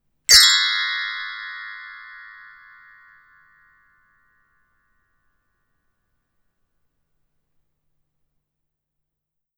bells-cluster.wav